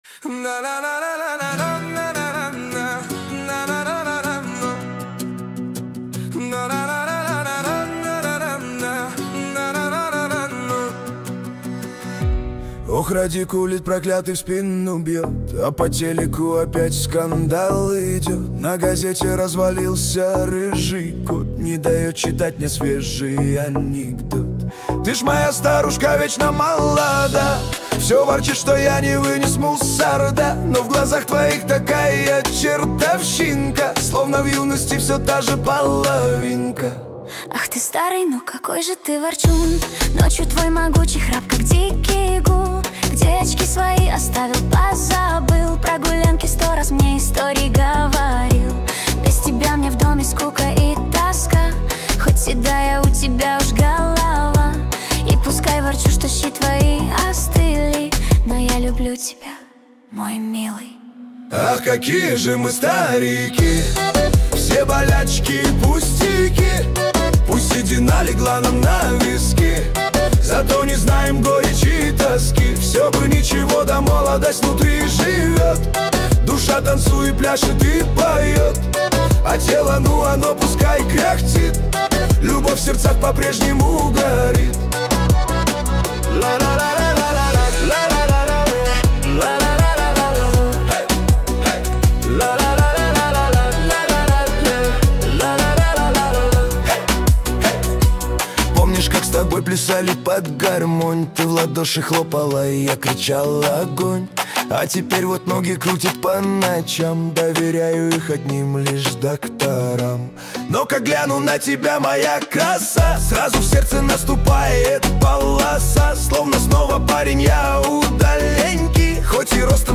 Качество: 223 kbps, stereo
Русские поп песни